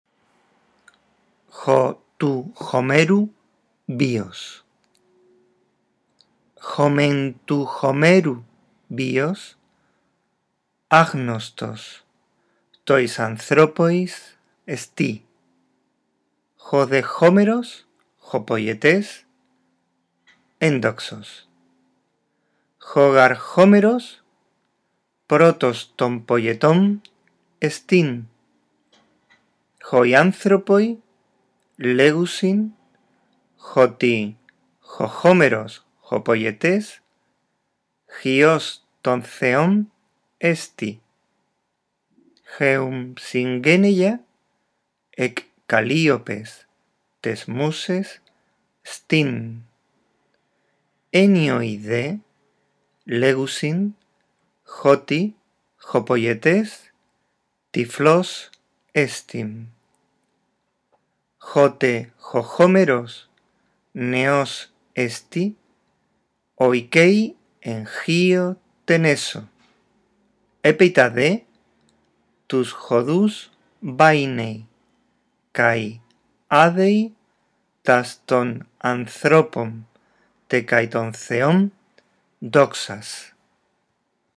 Después de haberlo leído una vez, escucha el archivo de audio y atiende a cómo se unen las palabras.